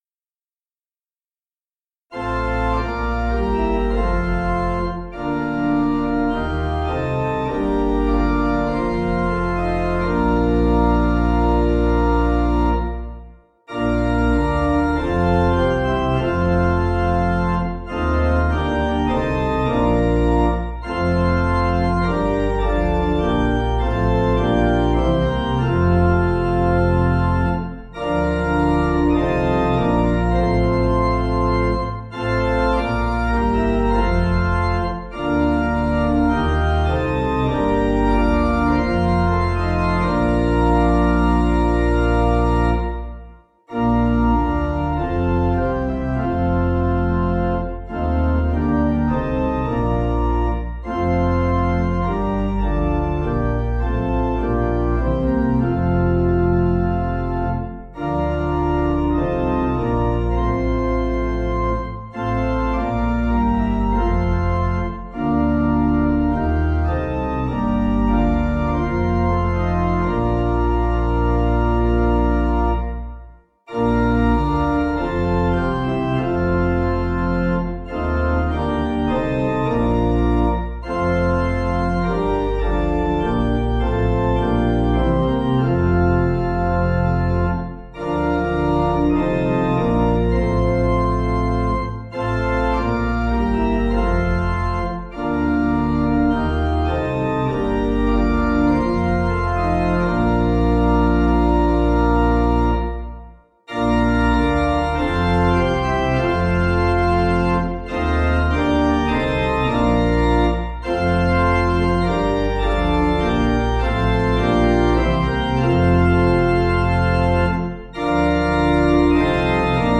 (CM)   4/Bb